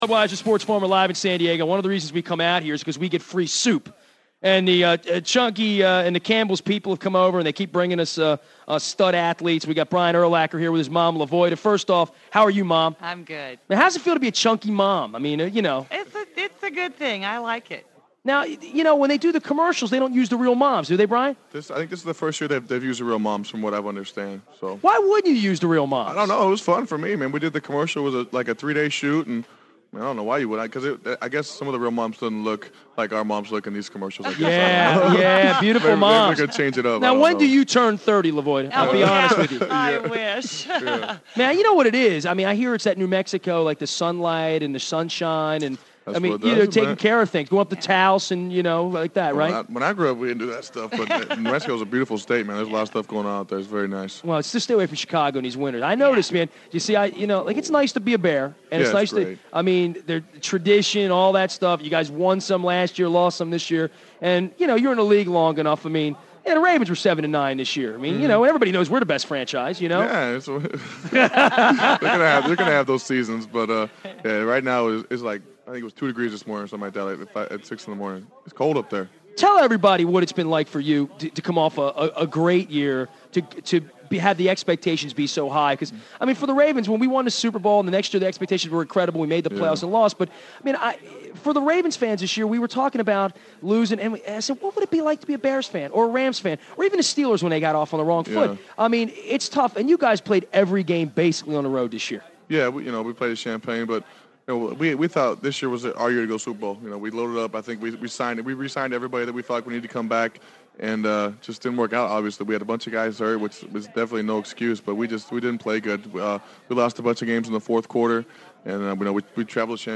Brian Urlacher San Diego Super Bowl Radio Row 2003